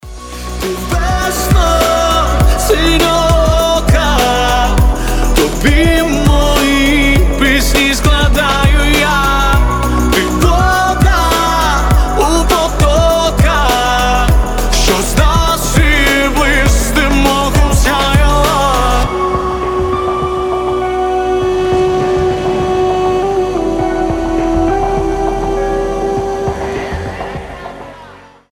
• Качество: 320, Stereo
красивый мужской голос
дуэт
дудук